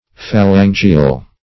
Phalangial \Pha*lan"gi*al\